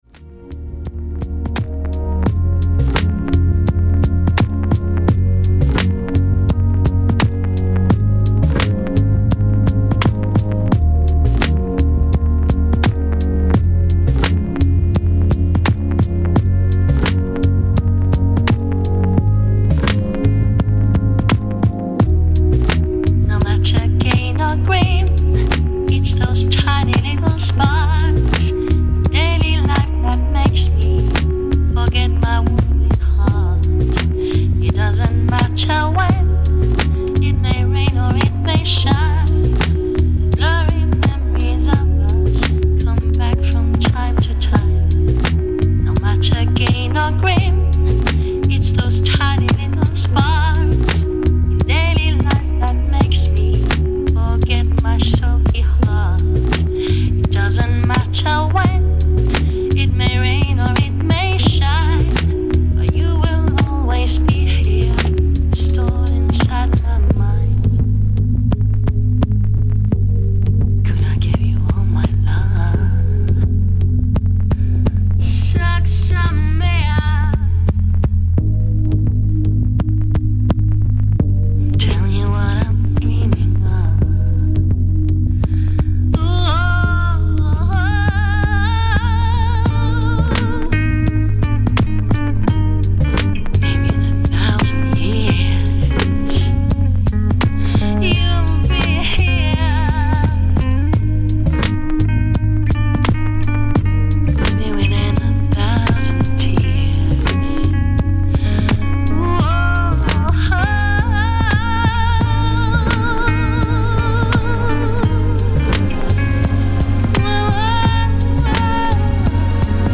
Жанр: Electronica-Chillout